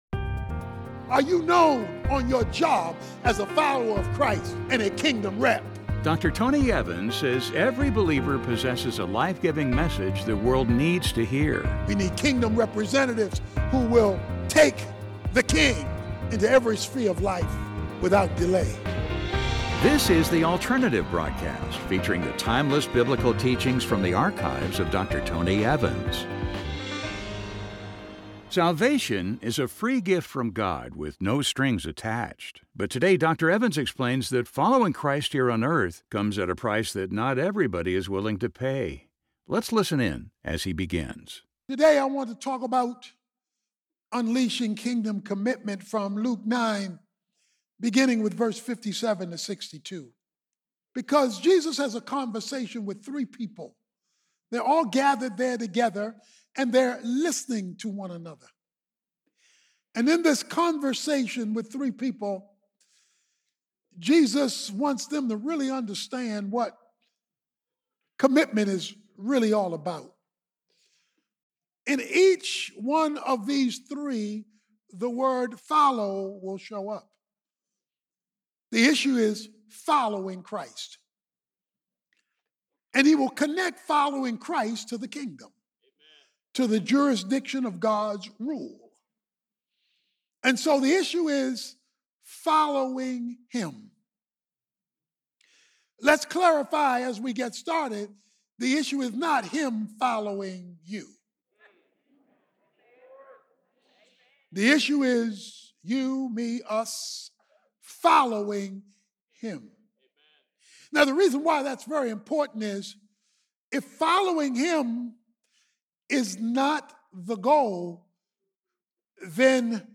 Salvation is a free gift from God with no strings attached. But in this message, Dr. Tony Evans explains that following Christ here on earth comes at a price that not everybody is willing to pay.